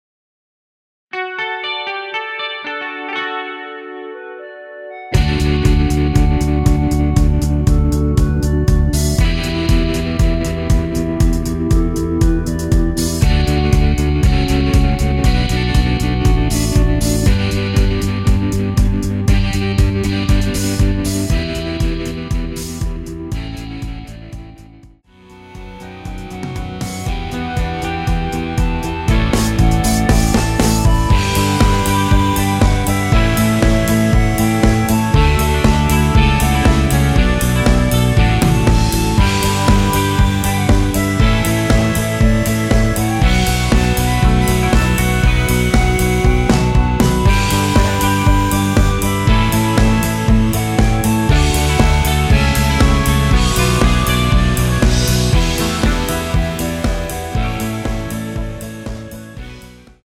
여성분이 부르실 수 있는 키의 MR입니다.
원키에서(+3)올린 멜로디 포함된 MR입니다.
앞부분30초, 뒷부분30초씩 편집해서 올려 드리고 있습니다.
(멜로디 MR)은 가이드 멜로디가 포함된 MR 입니다.